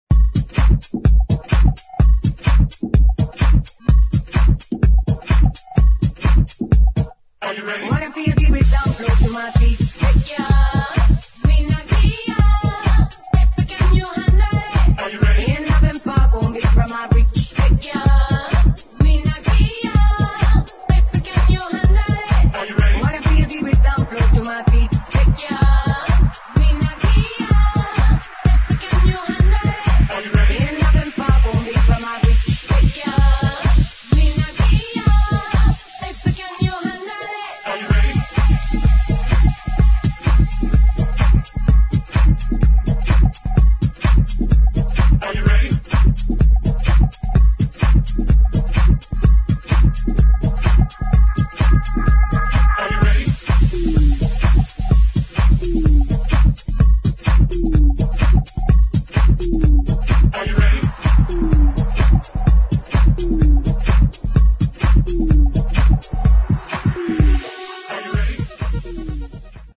[ HOUSE / TECH HOUSE ]